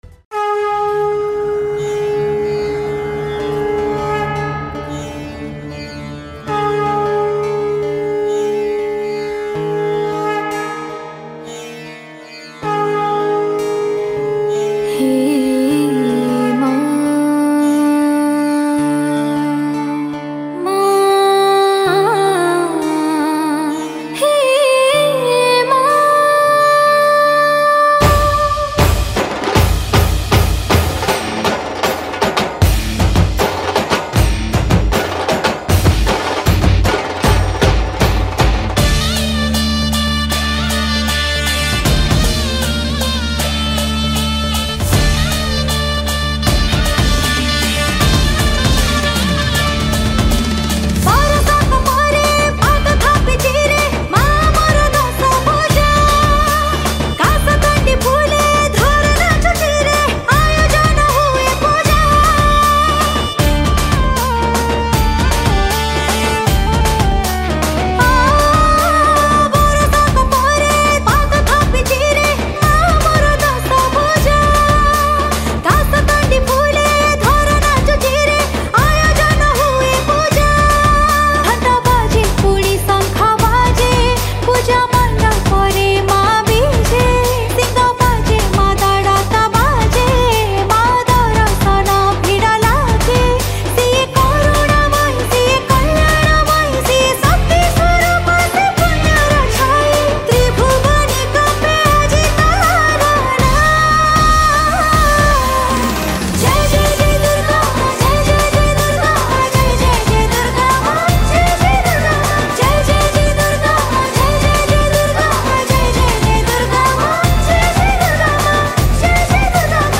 Durga Puja Special Song 2022 Songs Download